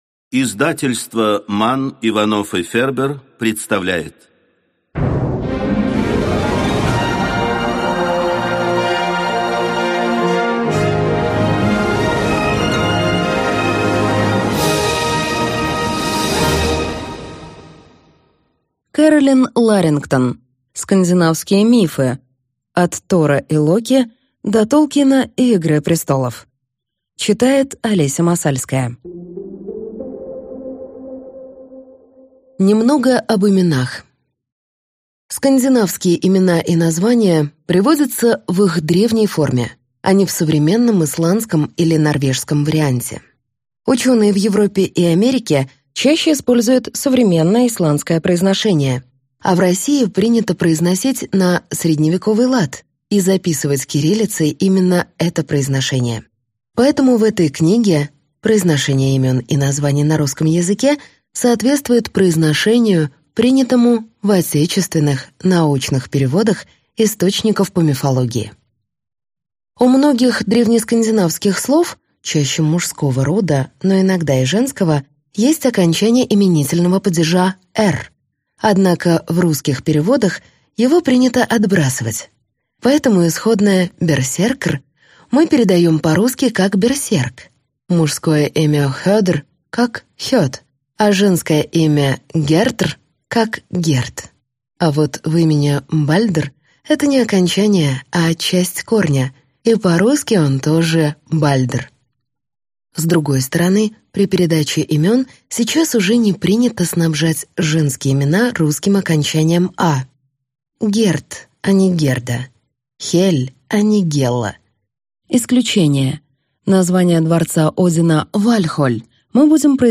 Аудиокнига Скандинавские мифы. От Тора и Локи до Толкина и «Игры престолов» | Библиотека аудиокниг